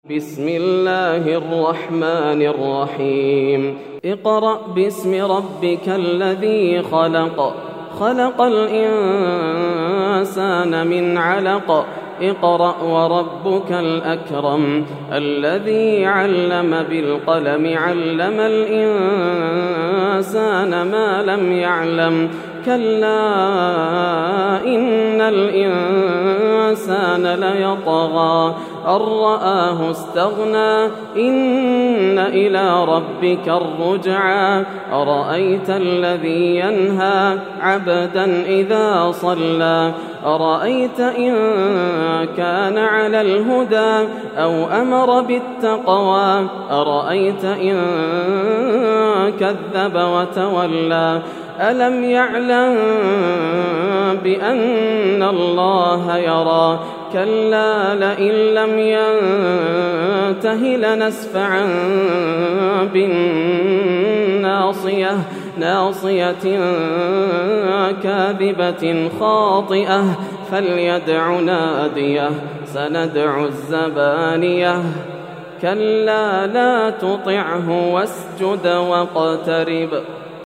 سورة العلق > السور المكتملة > رمضان 1431هـ > التراويح - تلاوات ياسر الدوسري